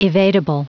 Prononciation du mot evadable en anglais (fichier audio)
Prononciation du mot : evadable